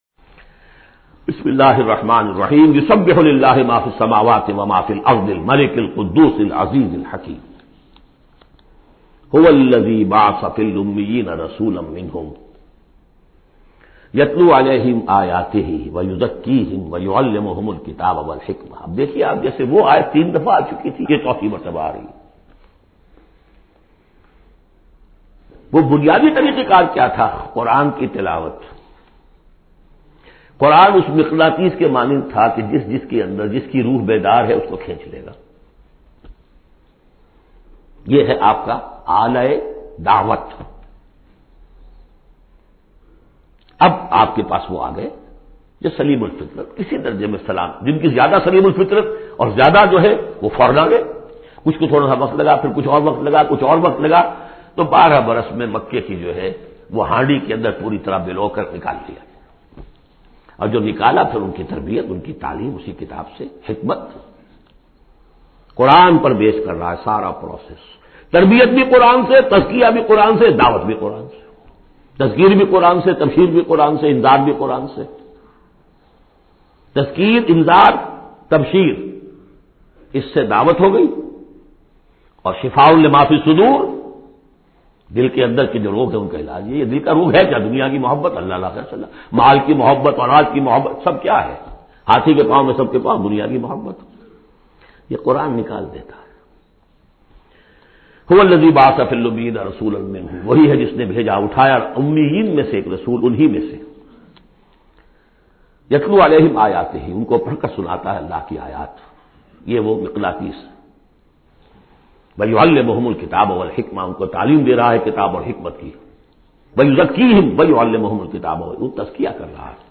Surah al Jumah is 62 chapter of Holy Quran. Listen online mp3 tafseer of Surah al Jumah in the voice of Dr Israr Ahmed.